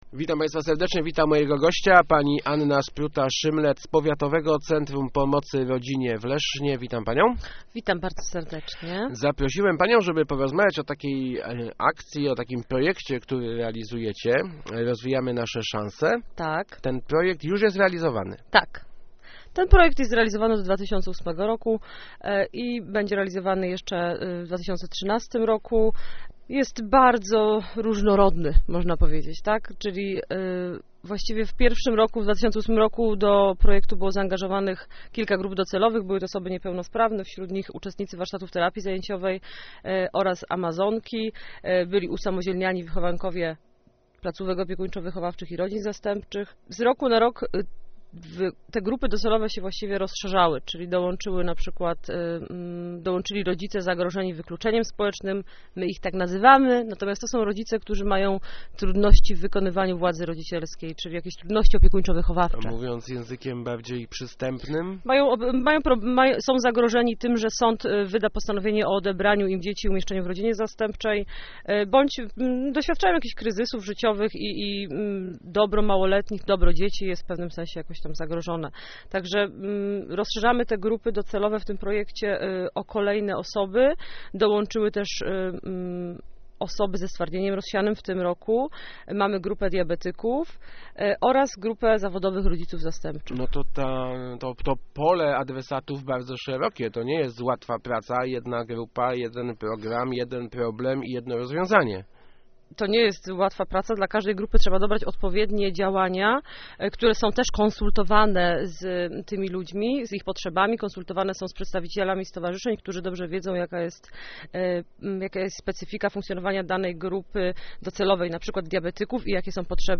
Start arrow Rozmowy Elki arrow Rozwijają szanse mieszkańców